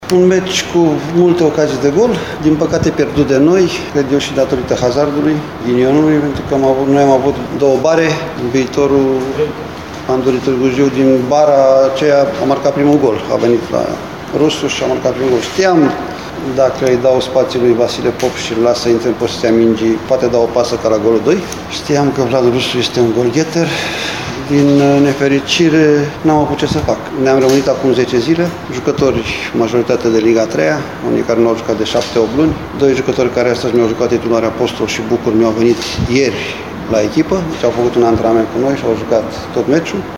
După meci au vorbit cei doi antrenori